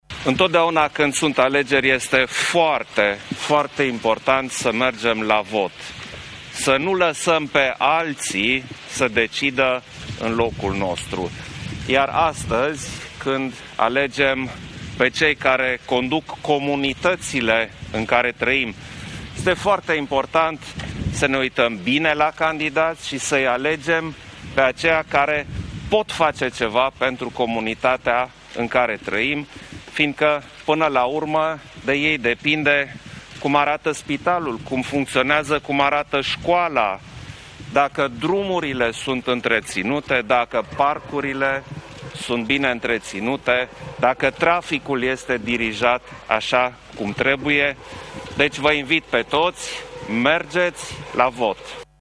La ieșirea din secția de votare acesta le-a cerut cetățenilor români să meargă la vot, arătând că aceste alegeri sunt foarte importante pentru comunitățile locale.